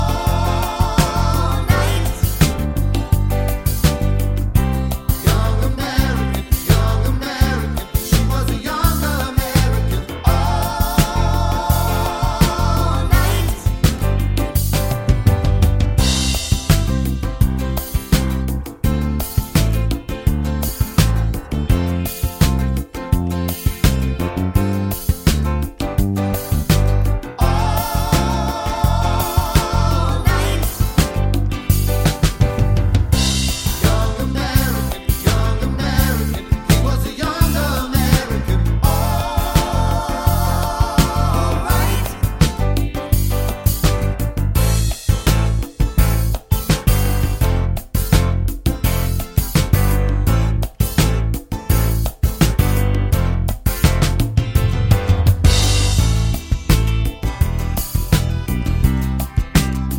no sax or Backing Vocals Pop (1980s) 3:15 Buy £1.50